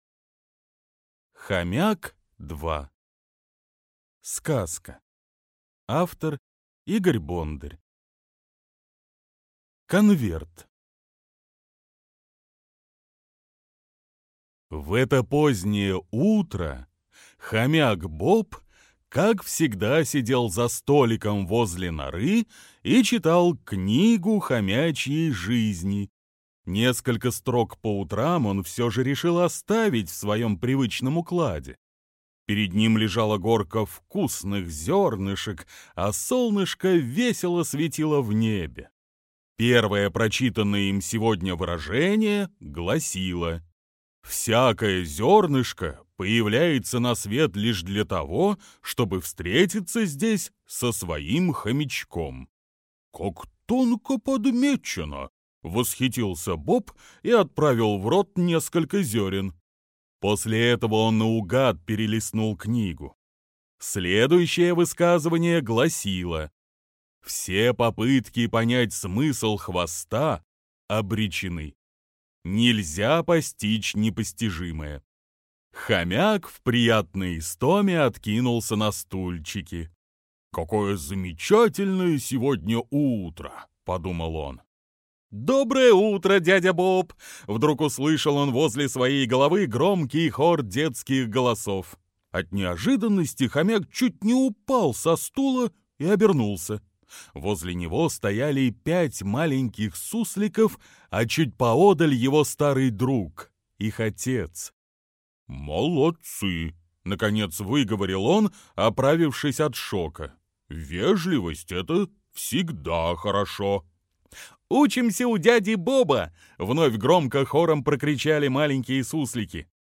Хомяк 2 - аудиосказка